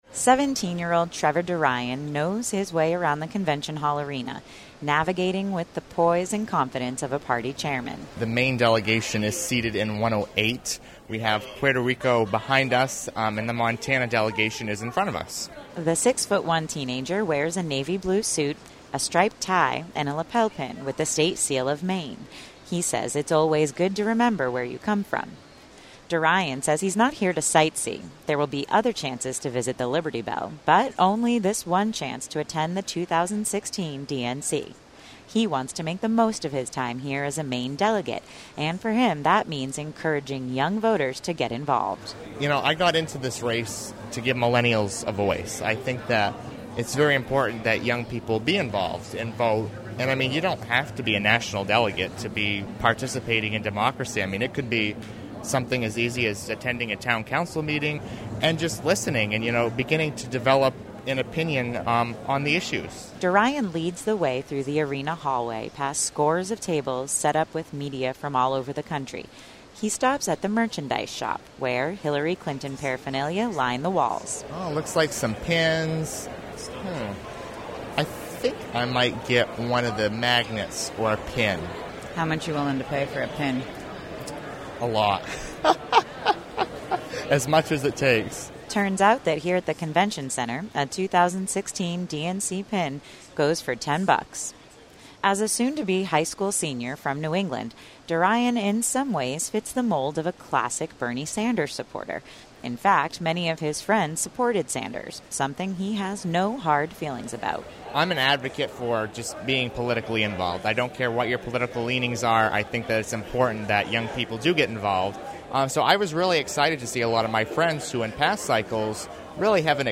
This report comes from the New England News Collaborative.